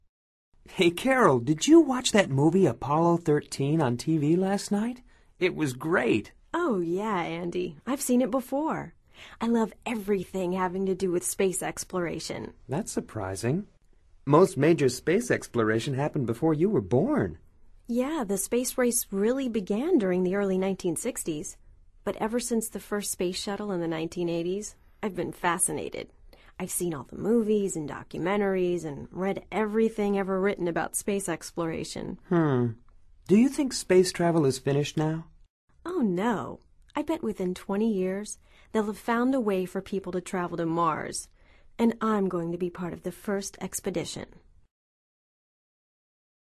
Escucha atentamente esta conversación entre Andy y Carol y selecciona la respuesta más adecuada de acuerdo con tu comprensión auditiva.